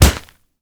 punch_grit_wet_impact_08.wav